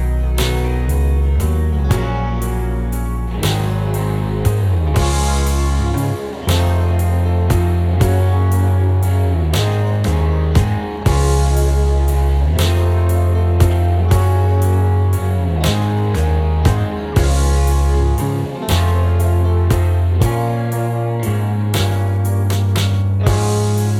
One Semitone Down Rock 4:10 Buy £1.50